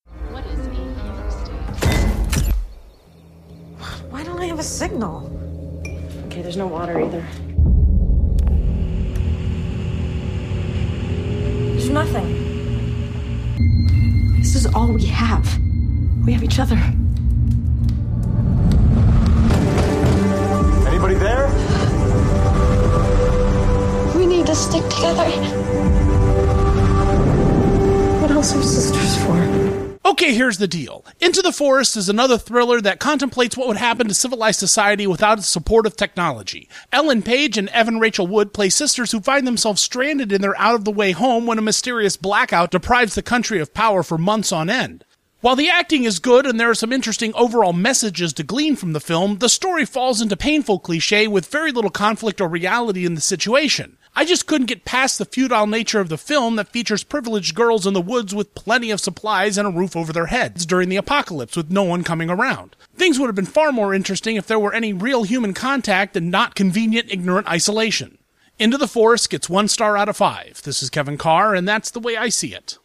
‘Into the Forest’ Radio Review